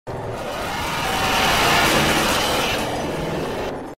В коллекции представлены различные аудиоэффекты: скрип дверей, жуткие звуки окружения, тематические мелодии – всё для полного погружения в атмосферу хоррора.